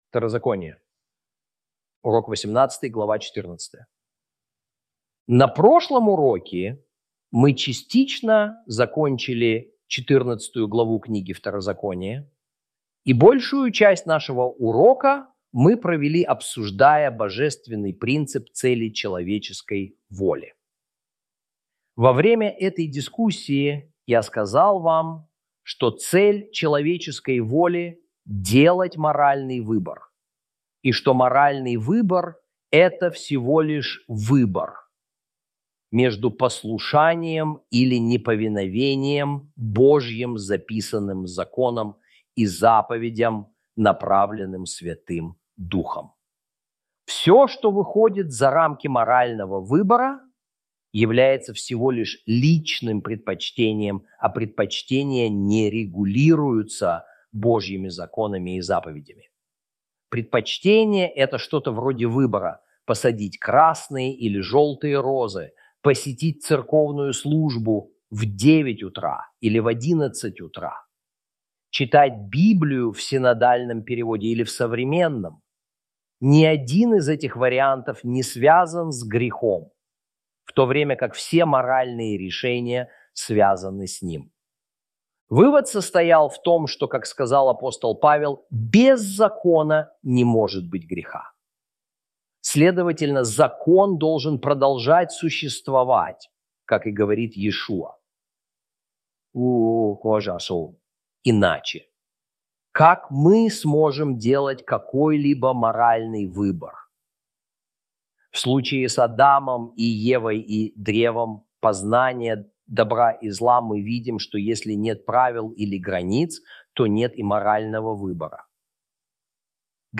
Урок 18 - Второзако́ние Ch 14 - Torah Class